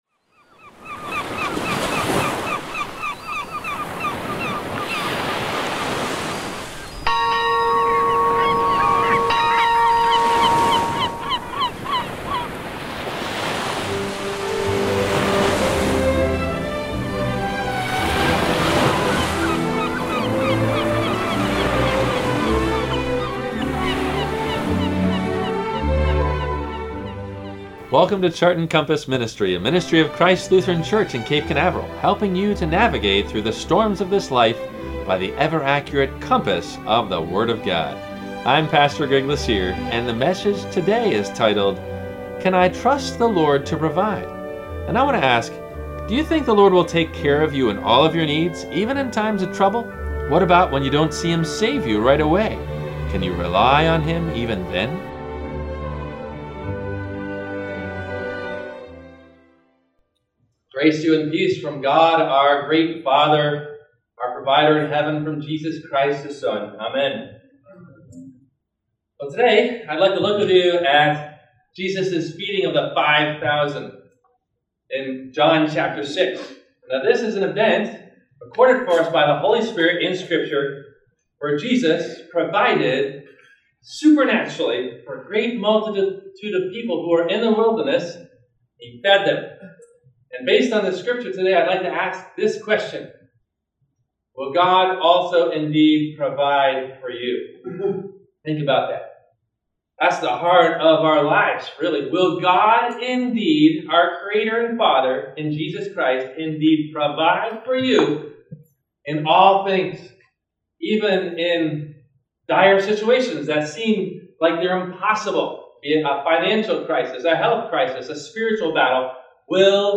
Can I Trust the Lord to Provide ? – WMIE Radio Sermon – July 21 2014